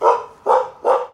hund-bellt-drei-mal-kurzer.mp3